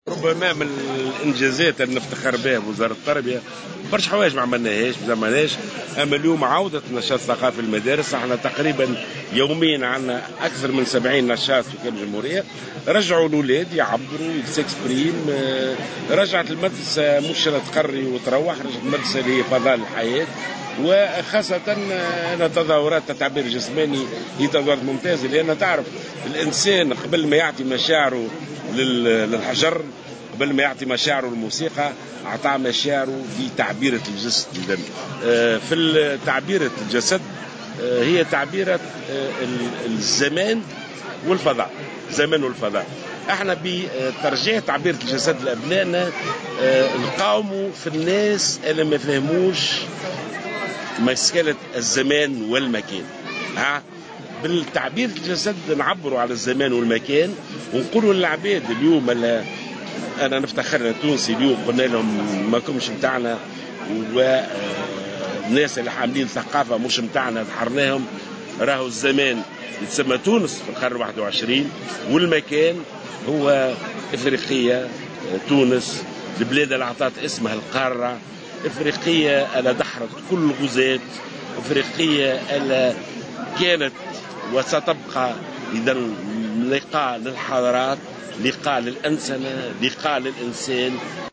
وأضاف الوزير في تصريح اليوم لمراسل "الجوهرة أف أم" على هامش إشرافه على الملتقى الوطني للتعبير الجسماني و الفنون التشكيلية بقصر العلوم بالمنستير، أنه يتم يوميا تنظيم أكثر من 70 نشاط ثقافي داخل المدارس في مختلف ولايات الجمهورية.